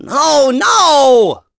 Ezekiel-vox-Deny.wav